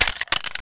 pistol_clipout.wav